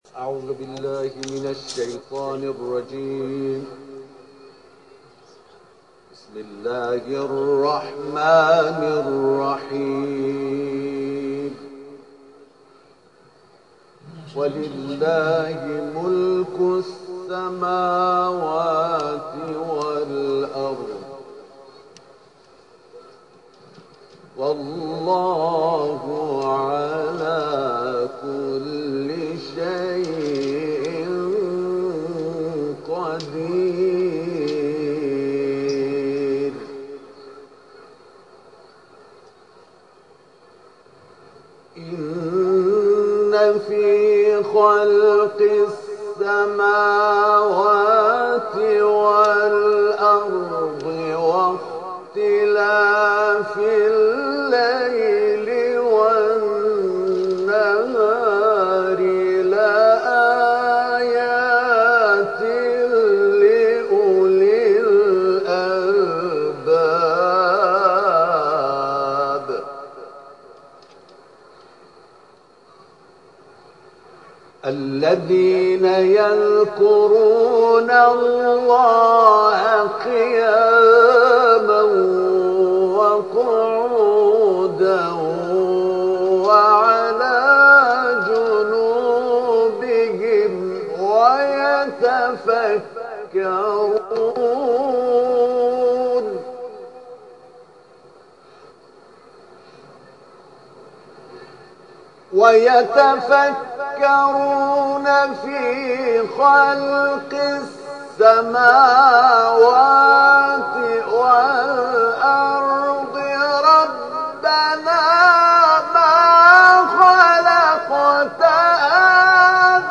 اولین تلاوت پخش زنده شبکه قرآن در سال 1396+دریافت
خبر ، خبرگزاری قرآن ، ایکنا ، فعالیت های قرآنی ، جلسات و محافل ، تلاوت ، مشهد ، حرم امام رضا (ع)